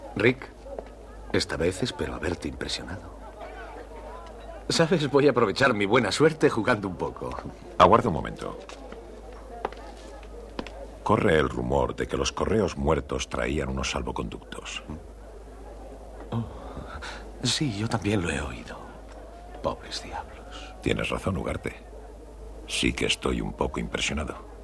La pista sonora en castellano, también en 1.0 monoaural, es igualmente la misma que incluía el DVD y suena igual de bien -sino un poco mejor- que la de la versión original.
audio en castellano del DVD, con el audio en castellano del Blu-Ray, notándose que la única diferencia significativa es que las voces son ahora un poco más graves -su tonalidad natural- al no estar sometidas al 4% de aceleración propia del DVD PAL.
Las voces se oyen perfectamente, con buena fidelidad, cosa que se aplica también a la la música. Hay muy poco ruido de fondo y no encontramos distorsiones acústicas.